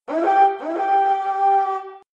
world-of-warcraft-flag-taken_25691.mp3